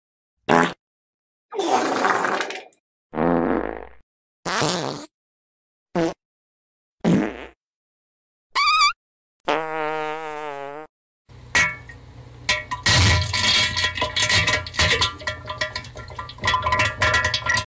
bbc-fart.mp3